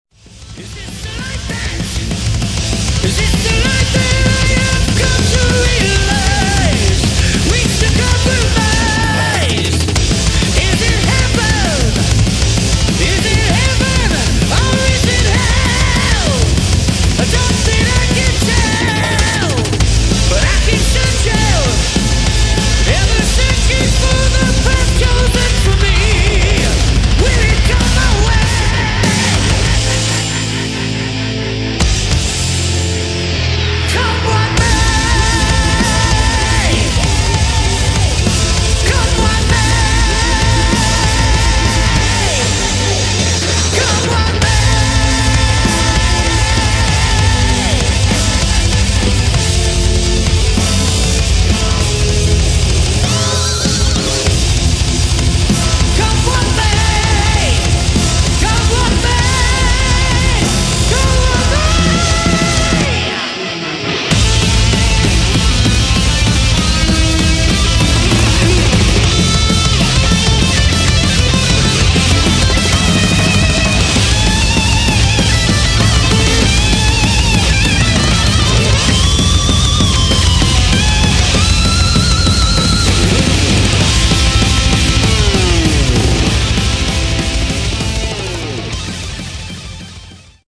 [ HARCORE ]
ブレイクコア/ハードコア/メタル